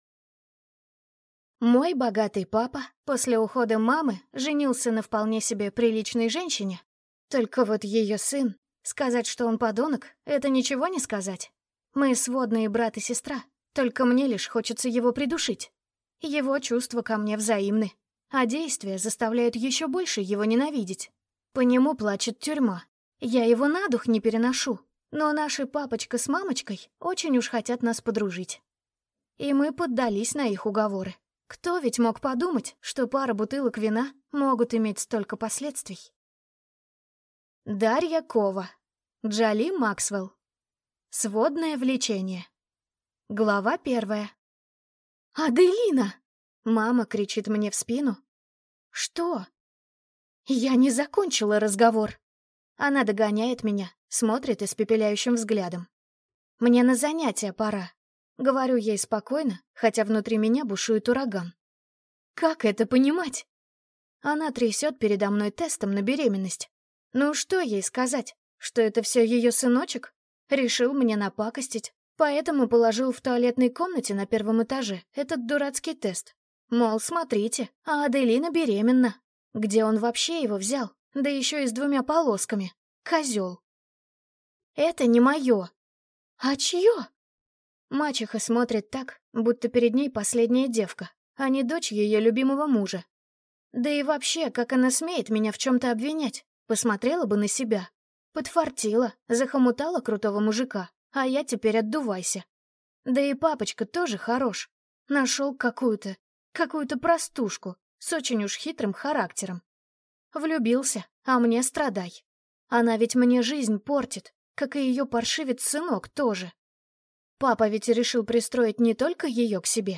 Аудиокнига Сводное влечение | Библиотека аудиокниг